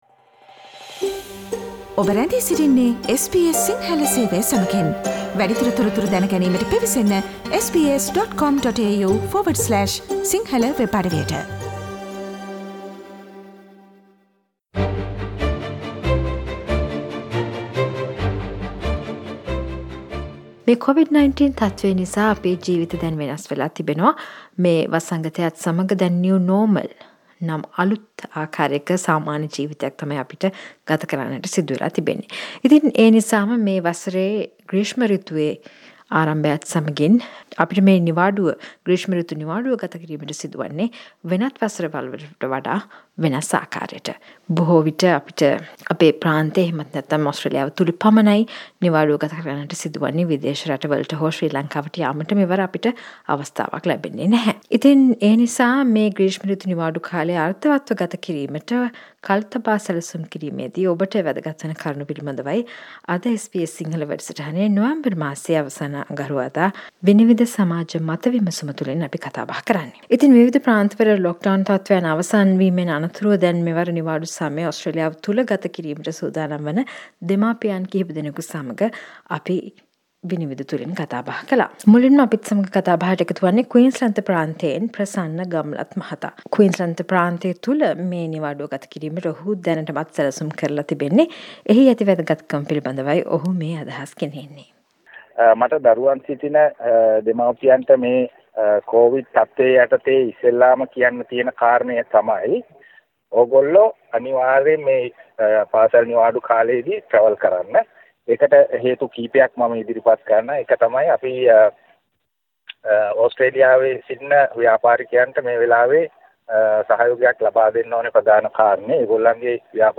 SBS Sinhala Vinivida monthly discussion is focusing on how to spend this summer holidays in a Covid safe way